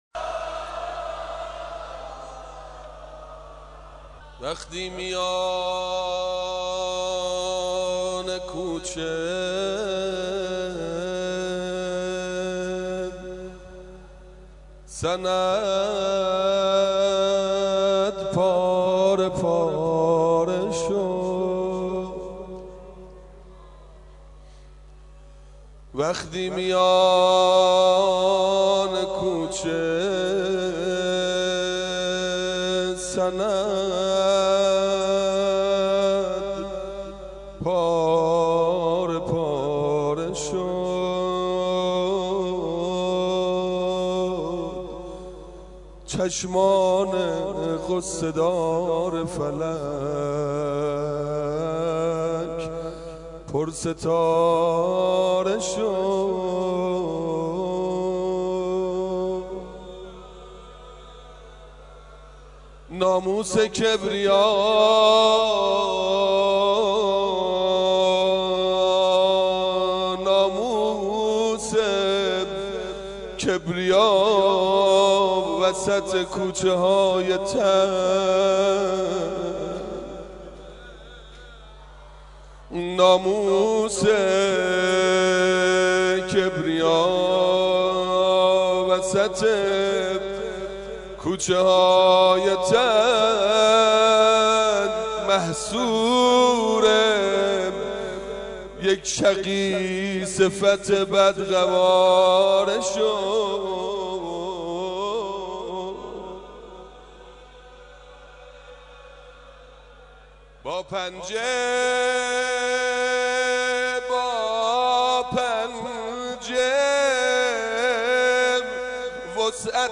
دعای افتتاح حاج منصور ارضی